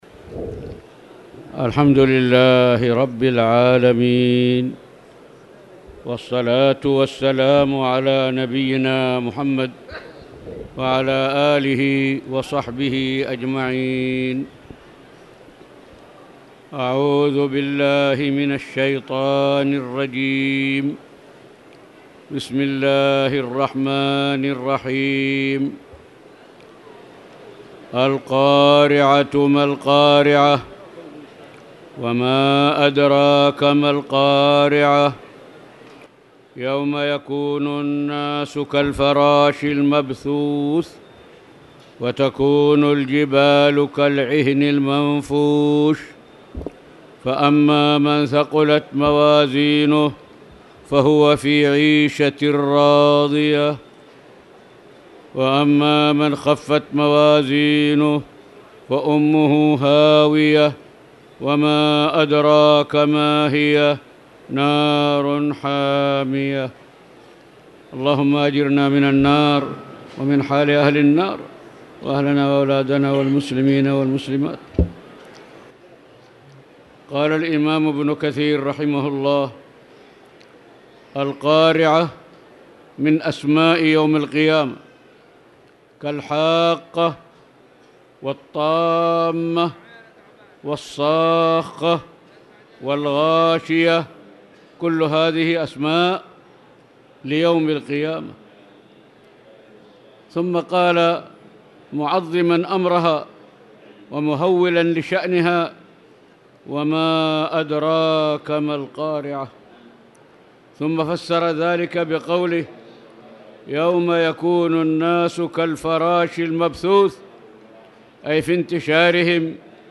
تاريخ النشر ١٩ رمضان ١٤٣٧ هـ المكان: المسجد الحرام الشيخ